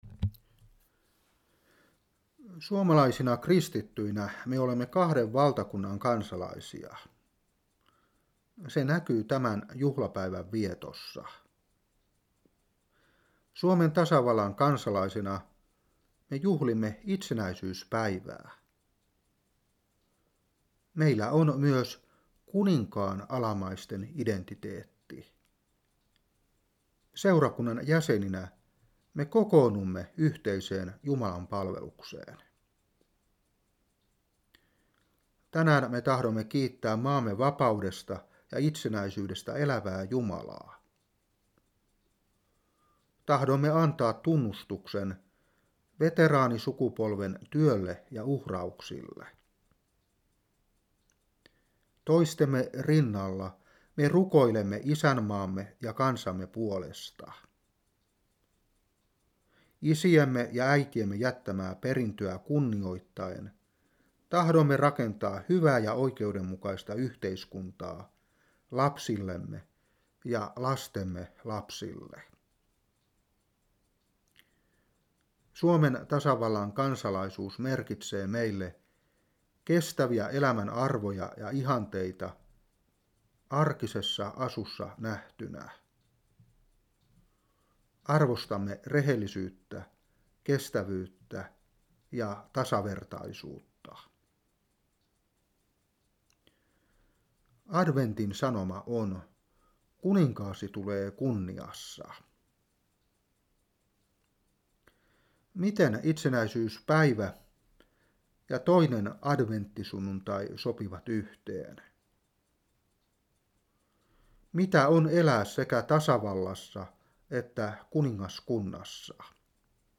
Saarna 2009-12.